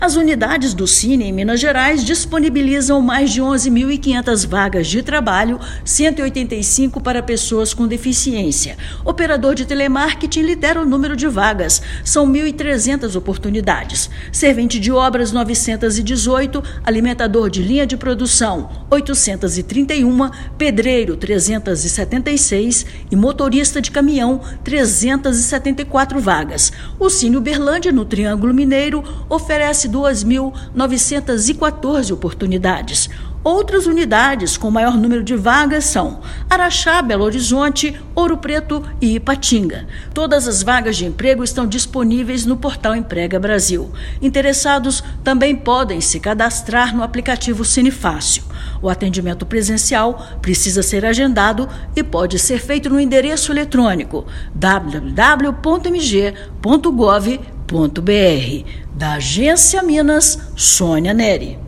Operador de telemarketing e servente de obras são as ocupações com o maior número de oportunidades. Ouça matéria de rádio.